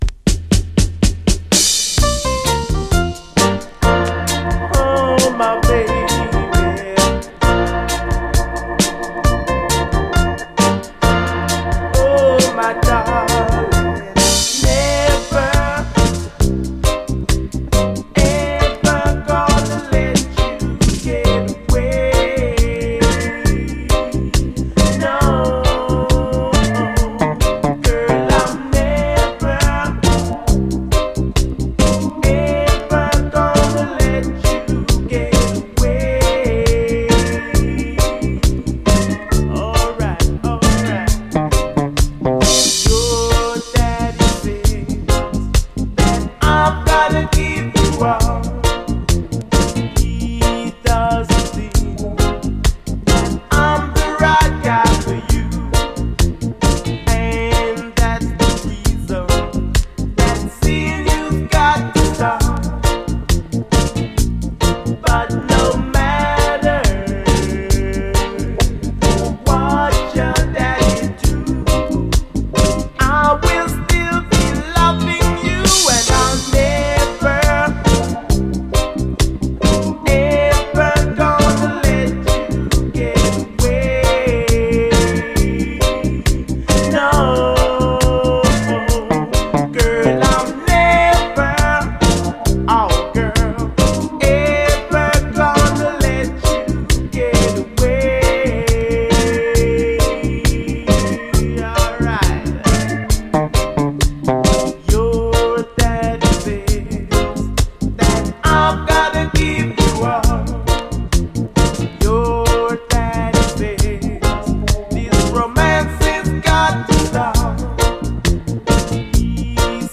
REGGAE
ソウルフルで哀愁系のUK産メロウ・ステッパー・レゲエ！
UKルーツ・レゲエらしい哀愁系でソウルフルな味わいです！